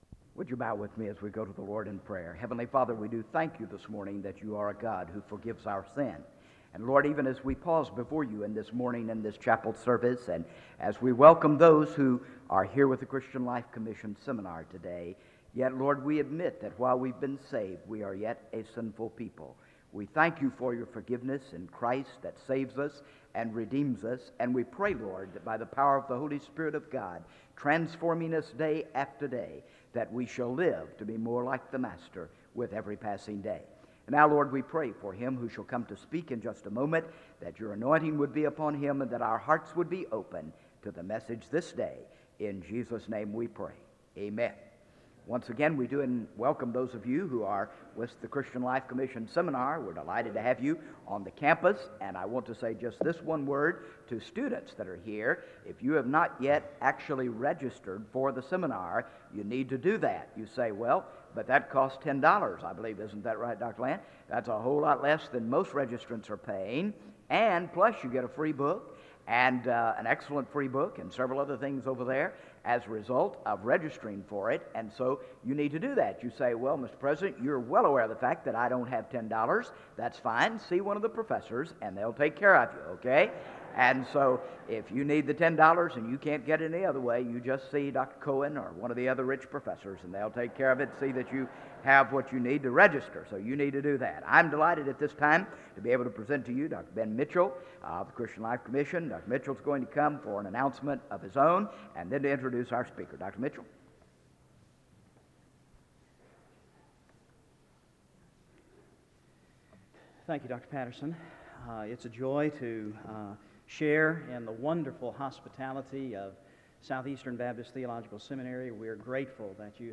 SEBTS Chapel - R. Albert Mohler, Jr. February 28, 1995
In Collection: SEBTS Chapel and Special Event Recordings SEBTS Chapel and Special Event Recordings - 1990s Miniaturansicht Titel Hochladedatum Sichtbarkeit Aktionen SEBTS_Chapel_R_Albert_Mohler_Jr_1995-02-28.wav 2026-02-12 Herunterladen